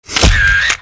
Dźwięki ostrzegawcze Fotoradary stacjonarne